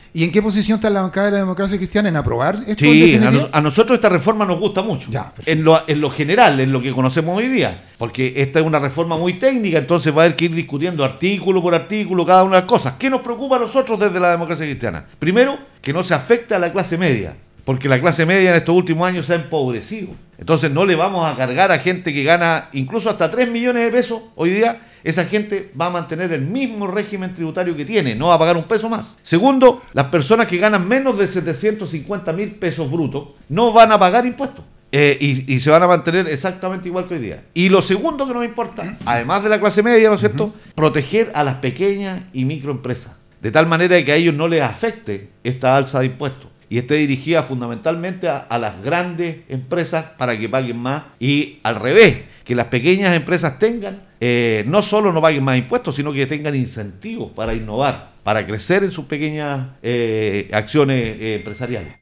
Ricardo Cifuentes, Diputado por la región de coquimbo, en conversación con Radio Los Vilos, se refirió a estos dos temas que han marcado la agenda noticiosa esta ultima semana.